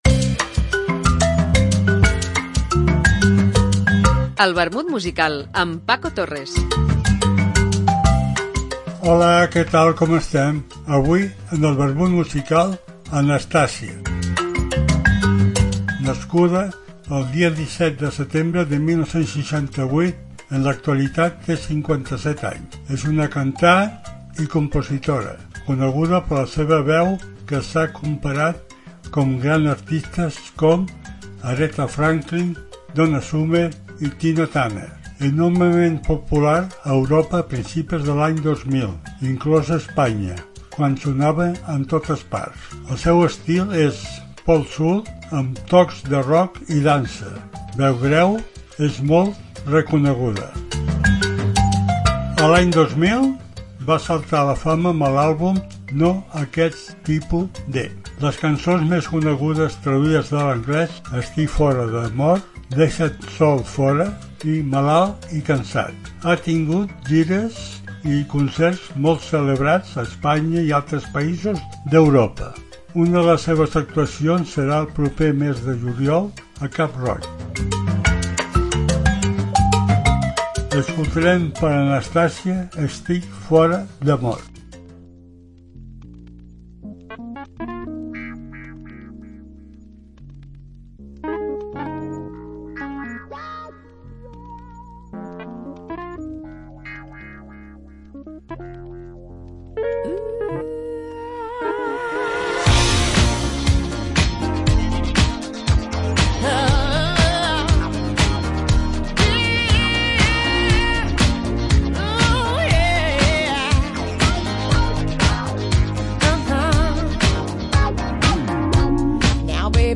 Una apunts biogràfics acompanyats per una cançó.